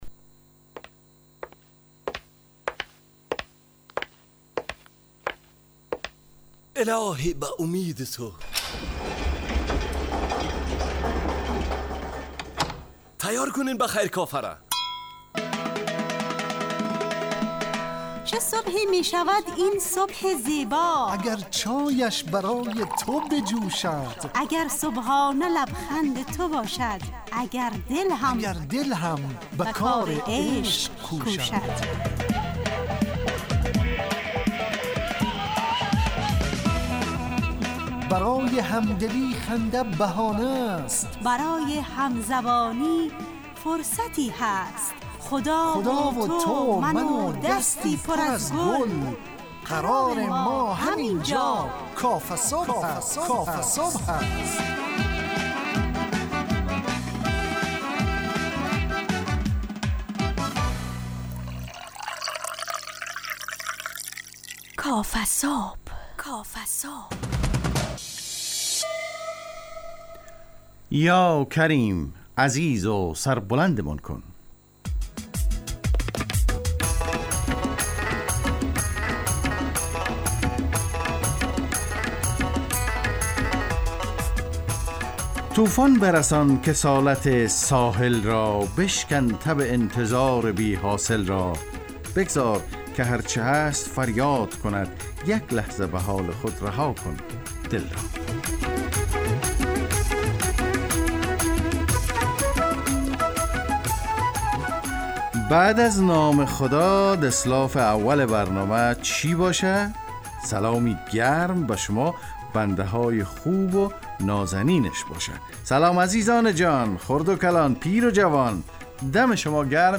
کافه‌صبح – مجله‌ی صبحگاهی رادیو دری با هدف ایجاد فضای شاد و پرنشاط صبحگاهی همراه با طرح موضوعات اجتماعی، فرهنگی و اقتصادی جامعه افغانستان با بخش‌های کارشناسی، نگاهی به سایت‌ها، گزارش، هواشناسی، صبح جامعه و صداها و پیام‌ها شنونده‌های عزیز